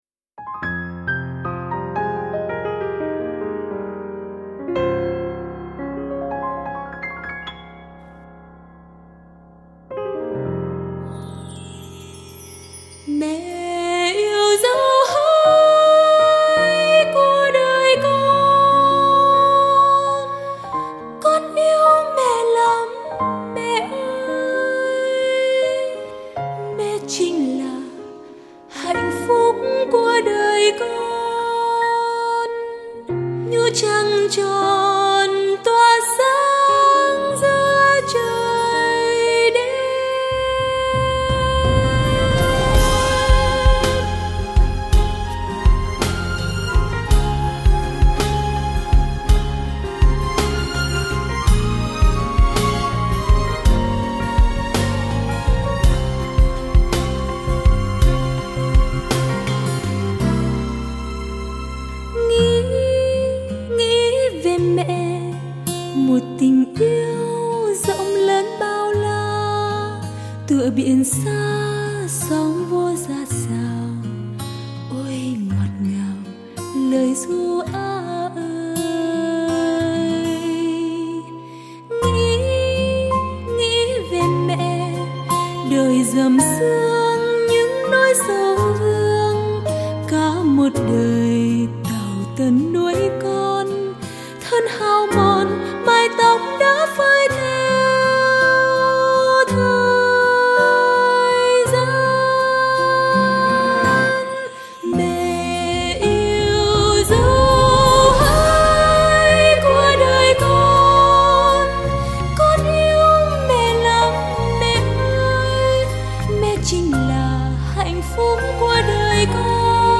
Category 🌾 Nhạc Thánh Ca